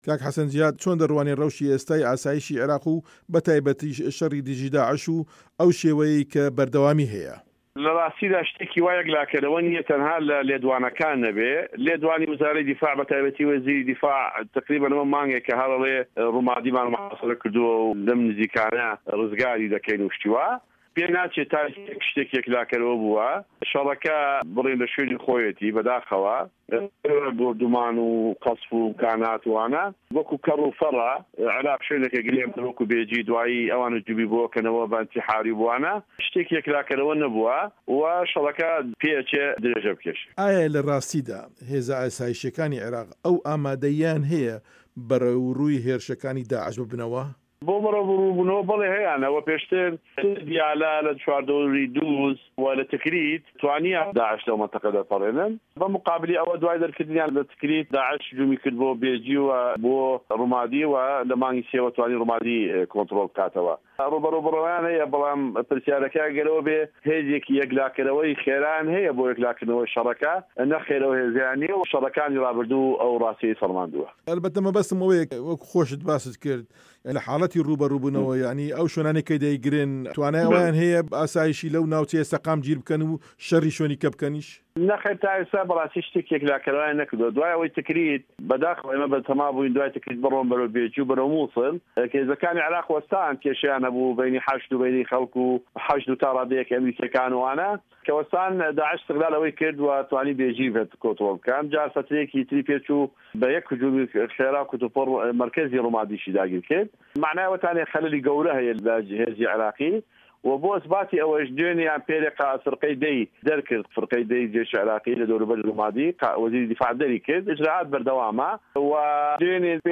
وتووێژ لەگەڵ حەسەن جیهاد